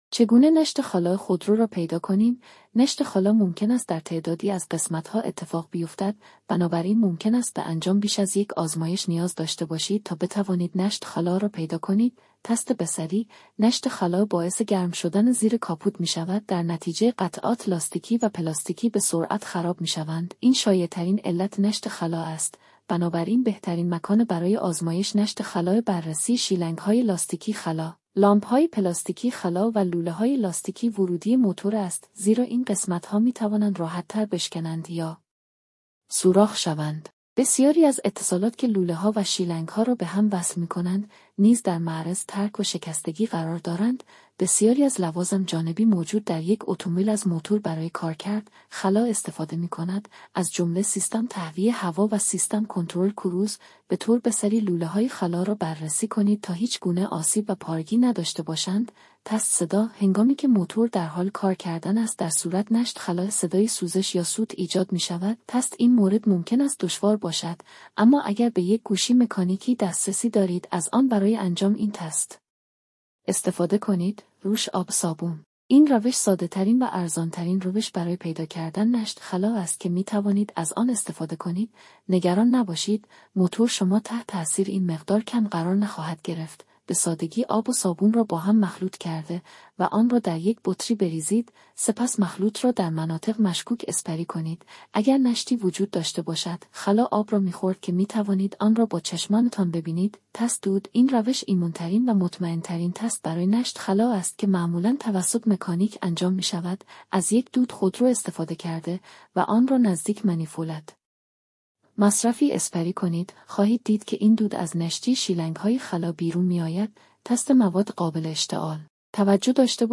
توجه : شما میتوانید کل این مطلب را بصورت پادکست (ساخته شده با هوش مصنوعی) گوش کنید و نیازی به خواندن مطلب نیست: